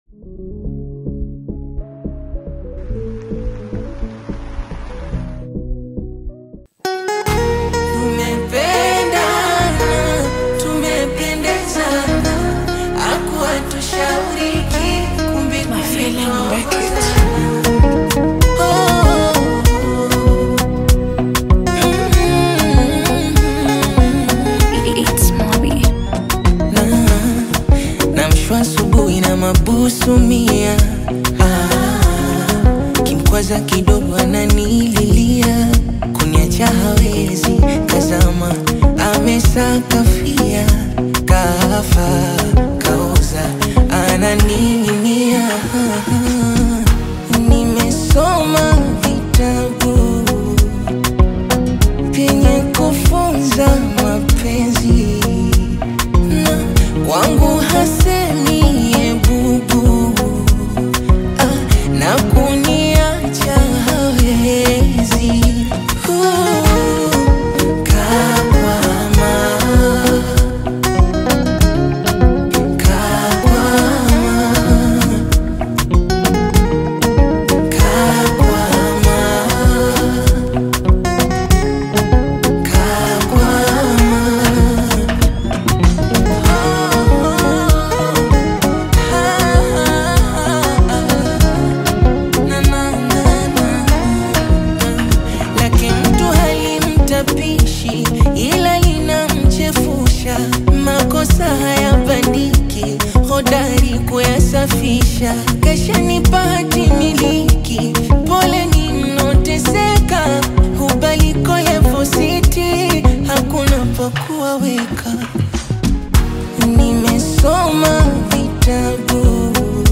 AudioBongo FlavaTanzanian Music